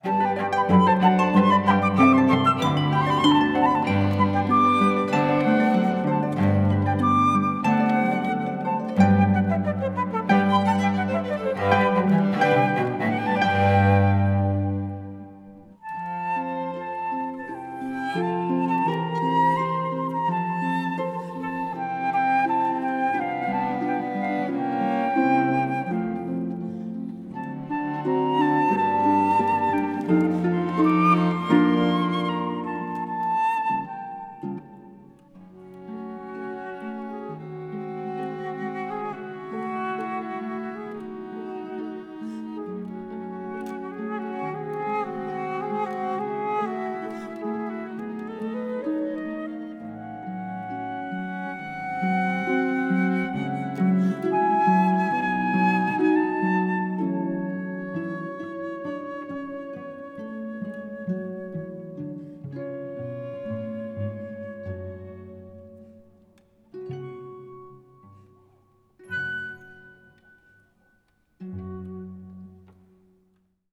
Live-Mittschnitt
Harfe, Flöte, Violine und Violoncello